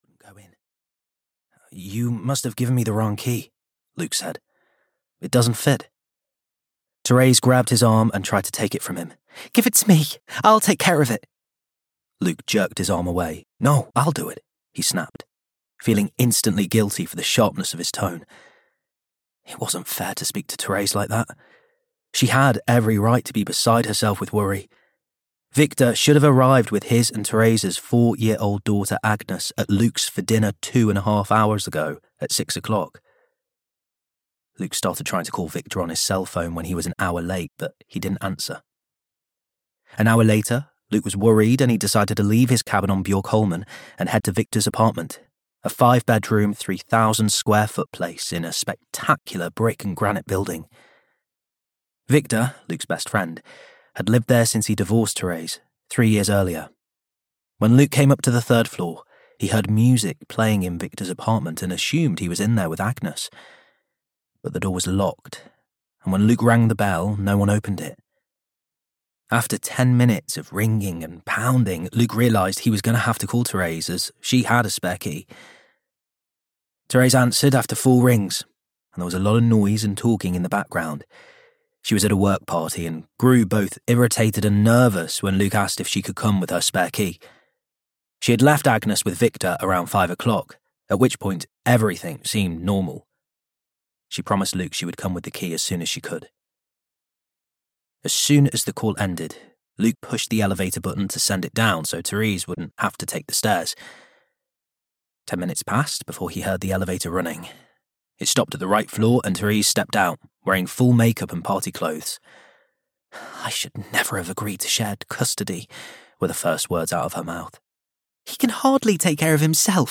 Kult (EN) audiokniha
Ukázka z knihy